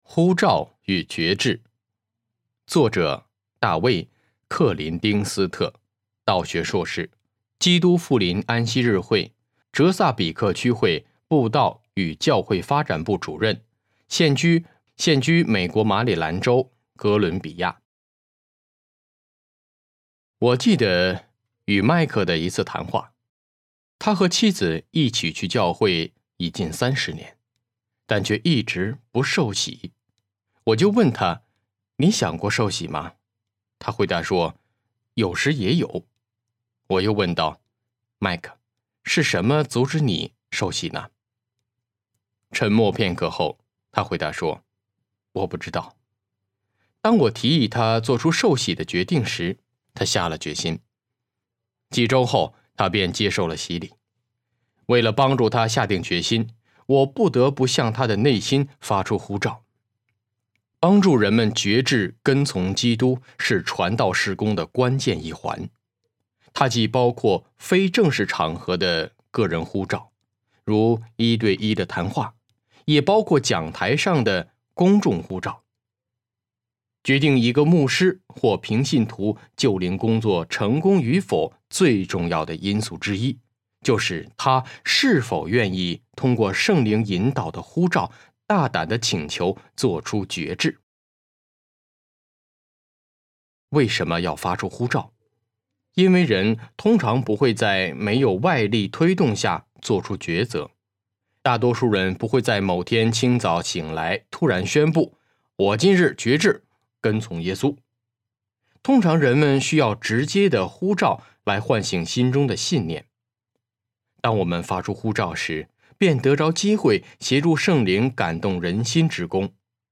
【传道者|中英文朗读】呼召与决志 Making Appeals and Getting Decisions